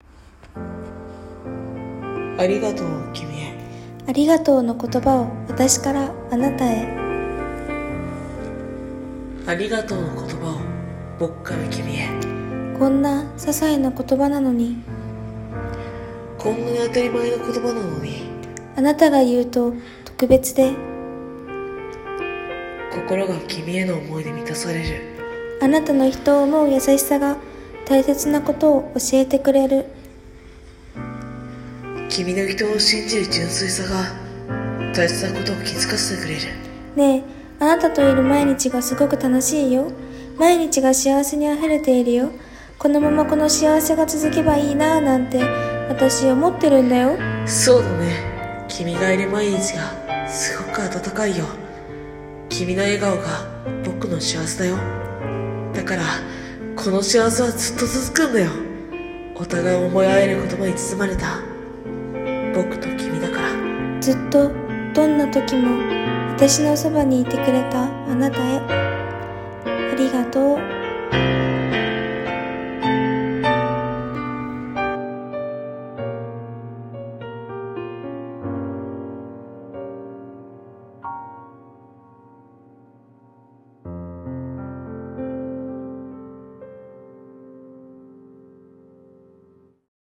二人用声劇【ありがとうを君へ】